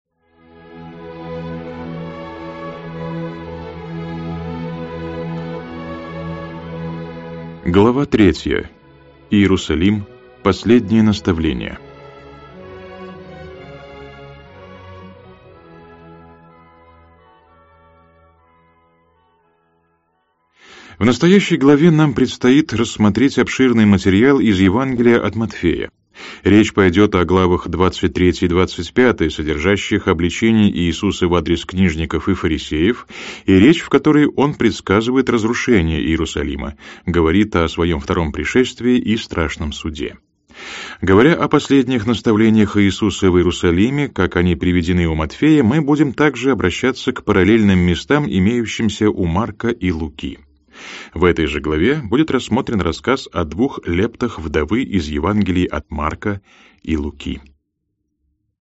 Аудиокнига Иисус Христос. Жизнь и учение.